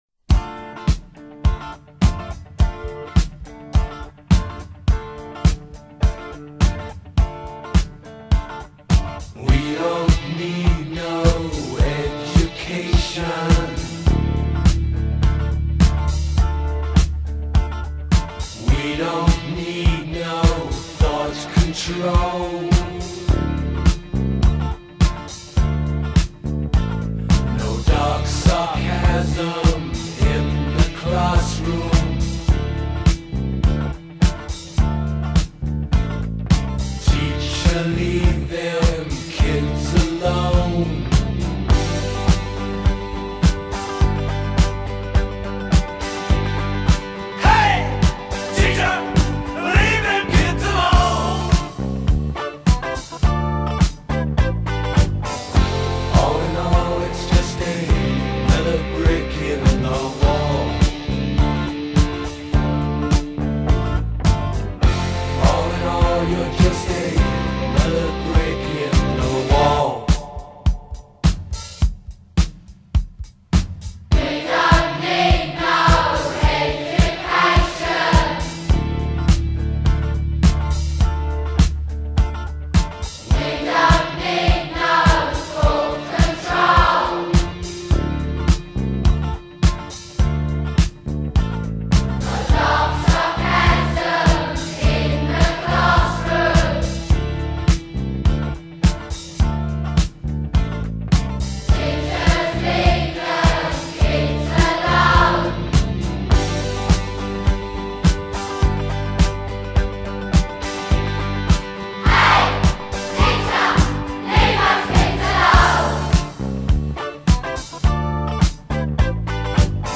shtoi elemente të disko